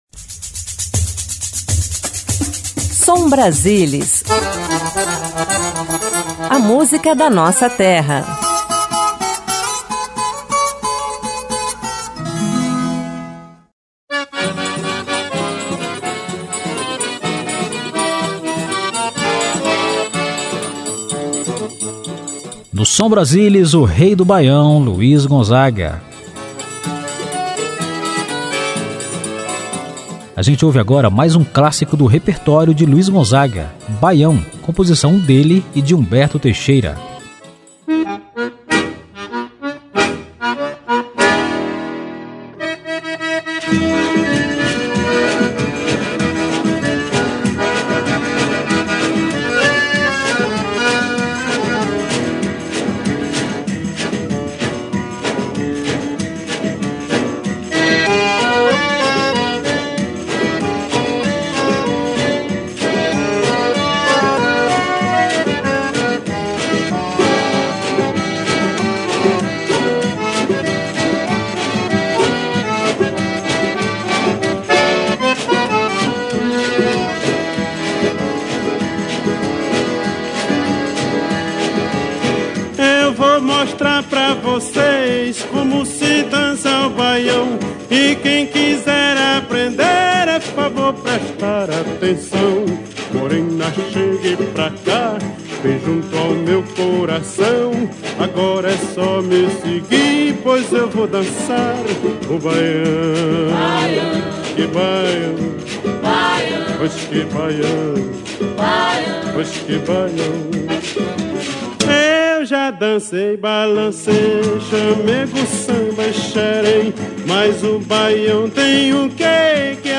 Ritmos nordestinos
Forró
Baião
Ao vivo